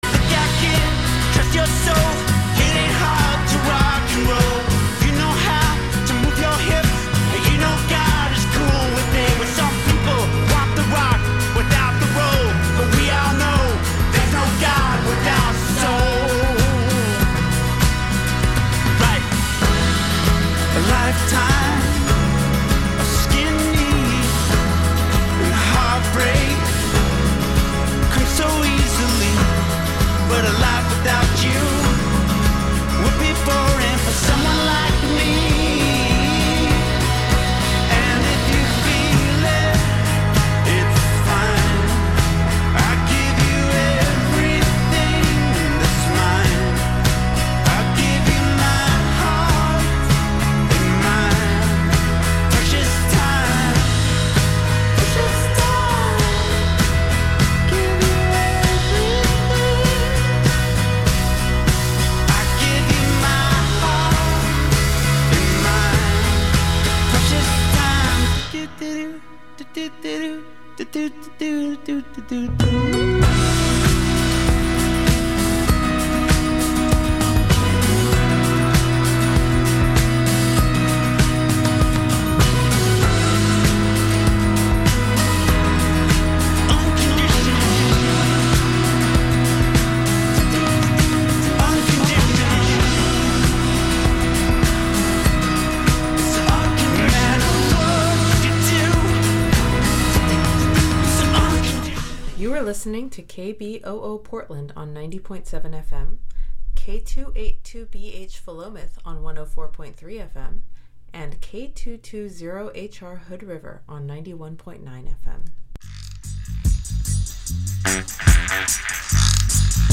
Conversations with leaders in personal and cultural transformation